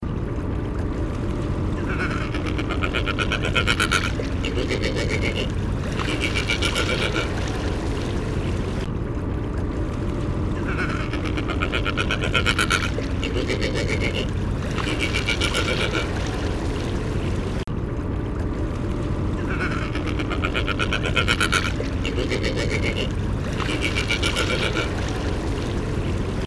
Macronectes giganteus - Petrel gigante común
Macronectes giganteus.wav